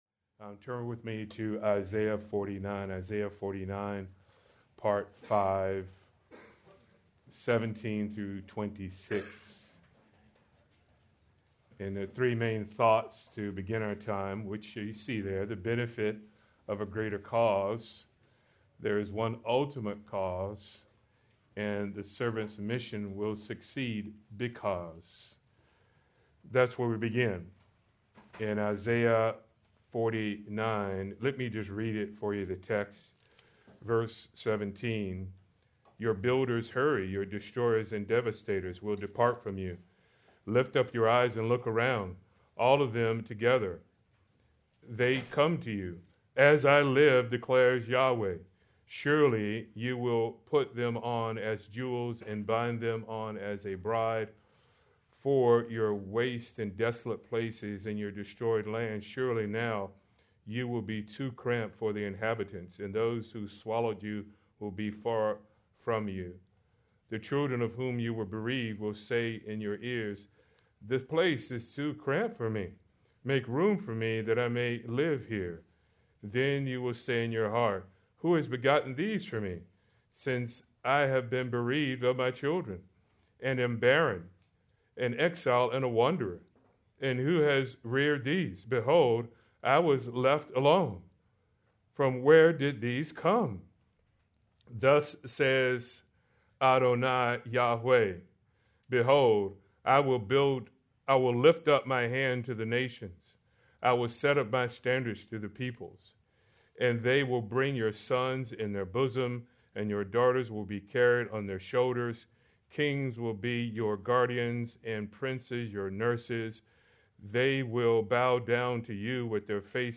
March 22, 2026 - Sermon